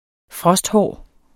Udtale [ ˈfʁʌsdˌhɒˀ ]